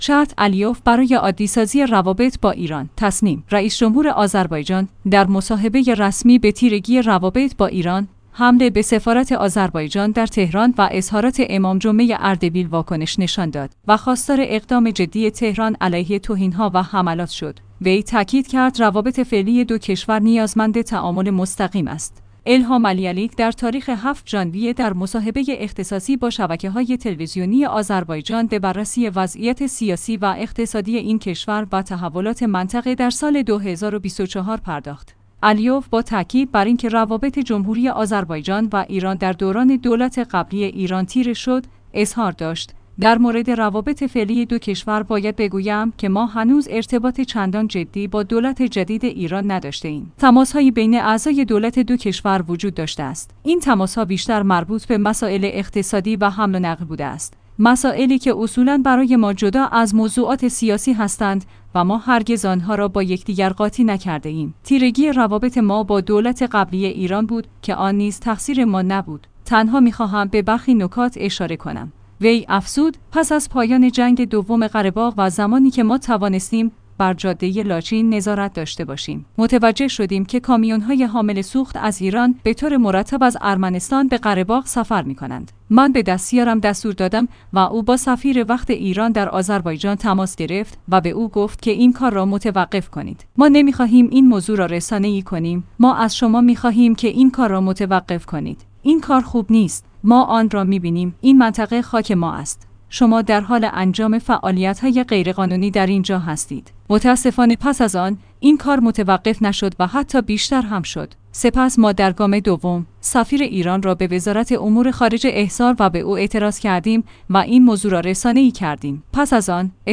تسنیم/ رئیس‌جمهور آذربایجان، در مصاحبه‌ رسمی به تیرگی روابط با ایران، حمله به سفارت آذربایجان در تهران و اظهارات امام‌جمعه اردبیل واکنش نشان داد و خواستار اقدام جدی تهران علیه توهین‌ها و حملات شد. وی تأکید کرد روابط فعلی دو کشور نیازمند تعامل مستقیم است.